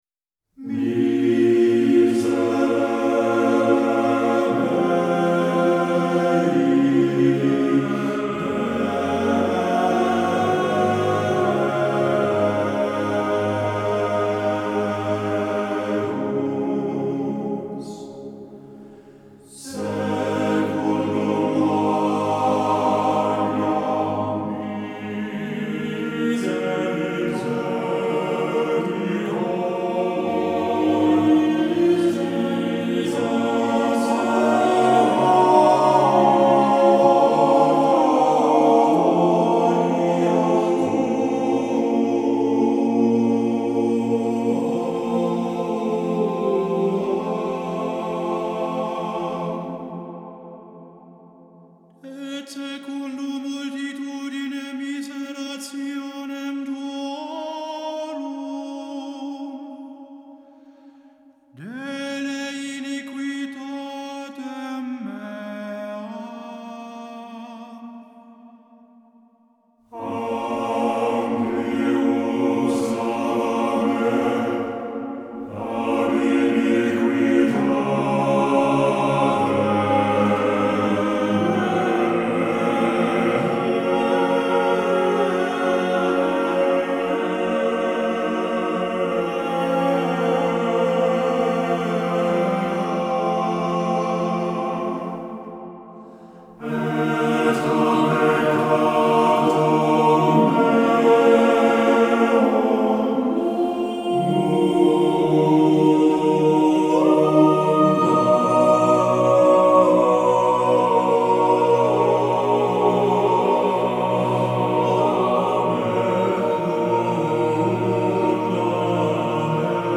Hi-Res Stereo
Genre : Pop